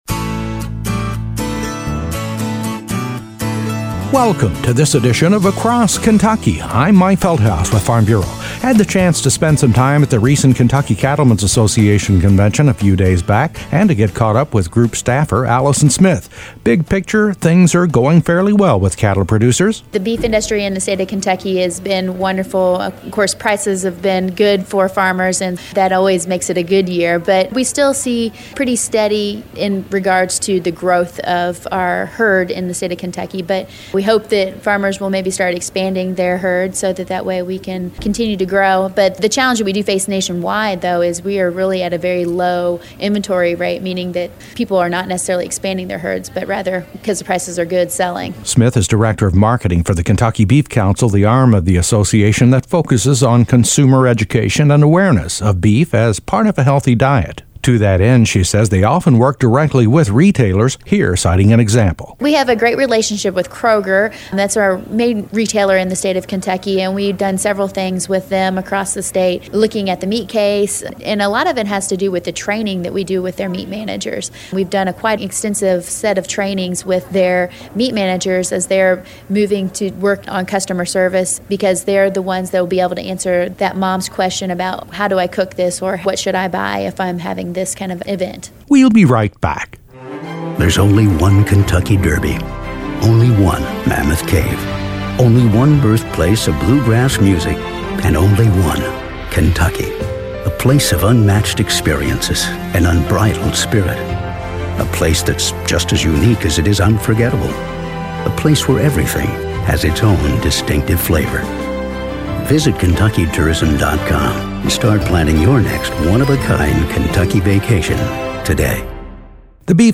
A feature report on the cattle industry’s consumer awareness efforts underway through the Ky Beef CA feature report on the cattle industry’s consumer awareness efforts underway through the Ky Beef Council.